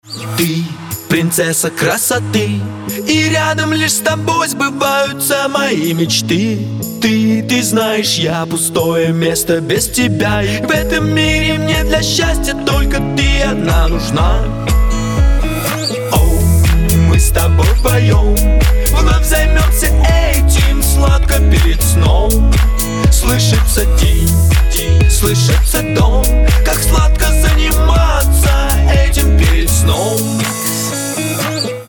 • Качество: 320, Stereo
deep house
Melodic
Знакомый мотив и русский вокал)